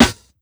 Numbers Snare.wav